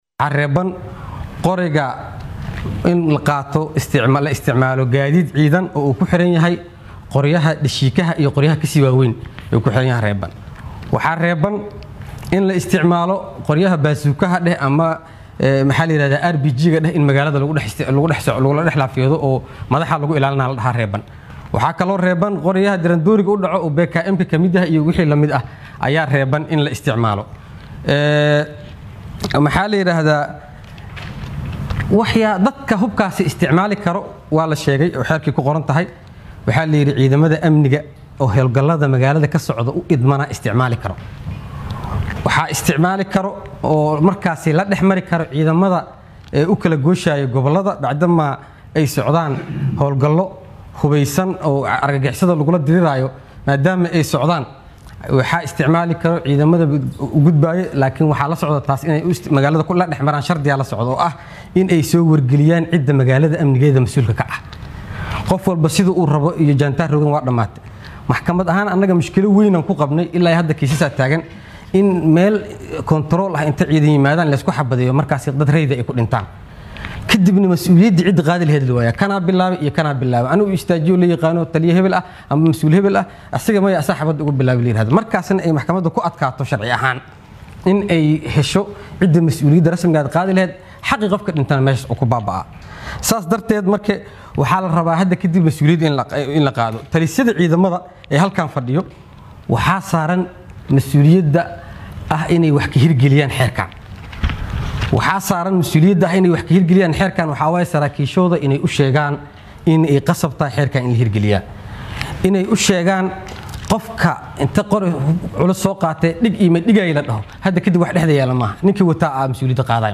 DHAGEYSO: Guddoomiyaha maxkamadda ciidamada qalabka sida oo ka hadlay xeerka xakameynta hubka caasimadda